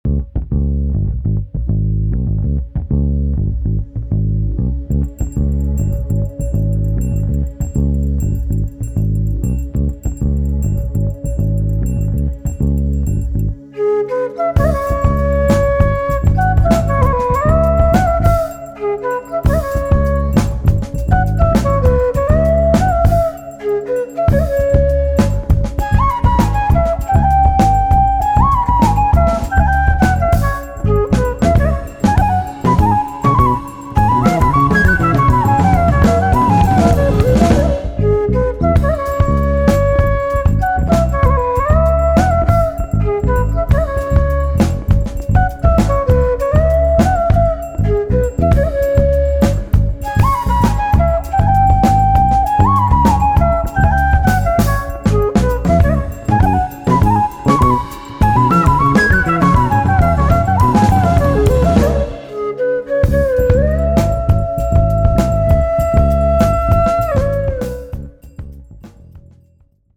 Genre: World Fusion.
bansuri and manjeera
drums and triangle
electric bass